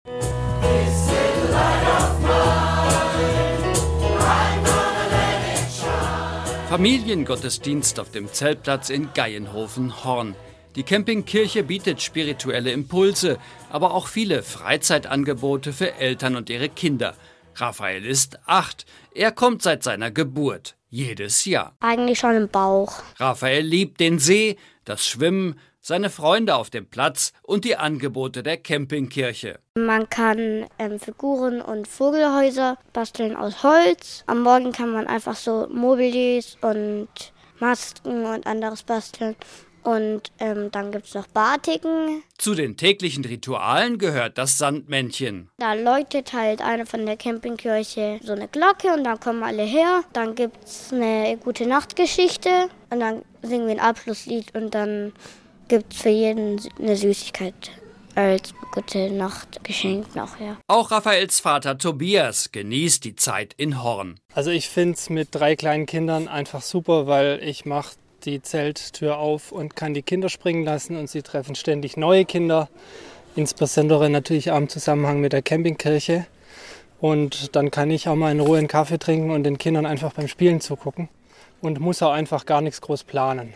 Horn   Das neue Radio Seefunk Campingkirche Radiobeitrag